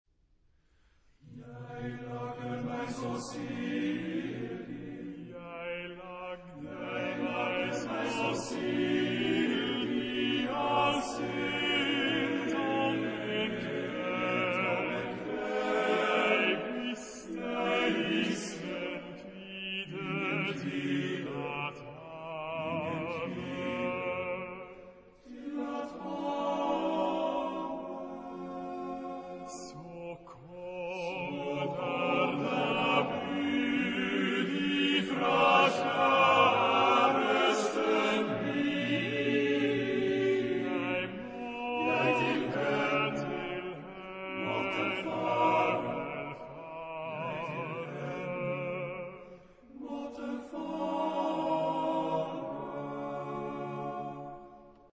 Genre-Style-Form: Romantic ; Secular ; Lied
Type of Choir: TTBB  (4 men voices )
Soloist(s): Baryton (1)  (1 soloist(s))
Tonality: B minor